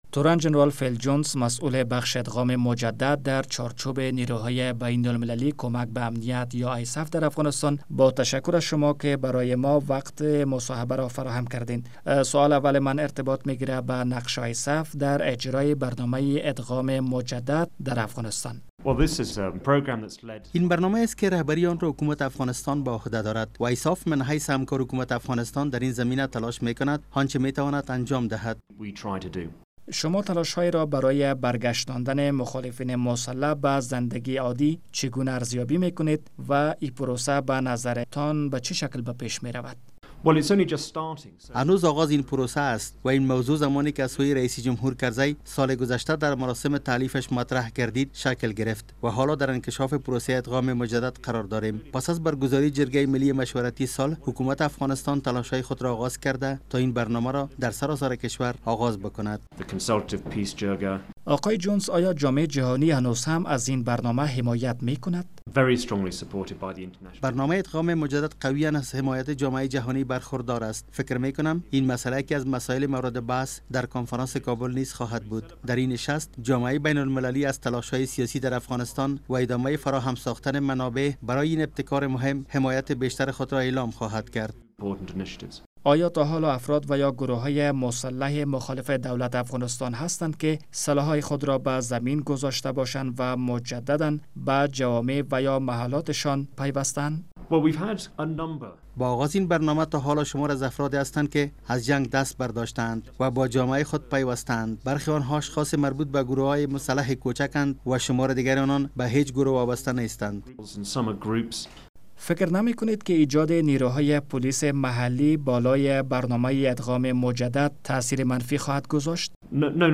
مصاحبه با جنرال فل جونس در مورد برنامهء برگشتاندن مخالفین مسلح به زنده گی عادی